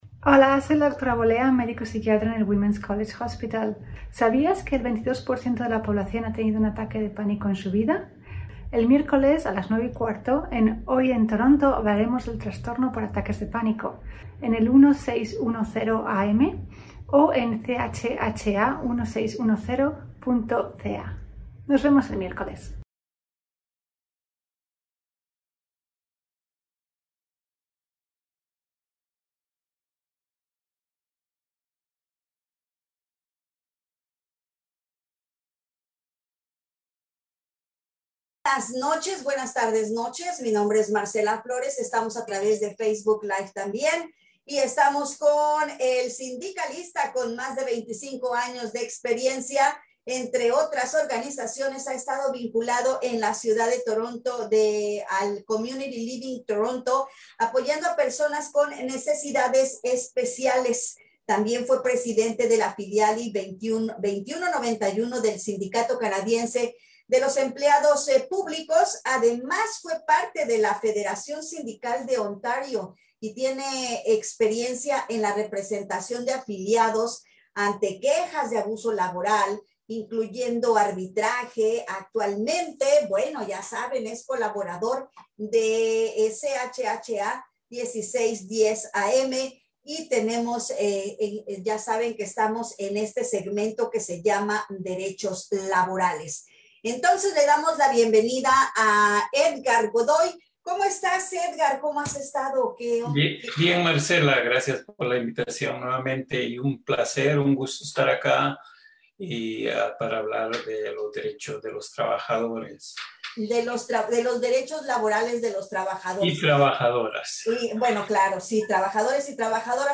El tema fue desarrollado en la siguiente entrevista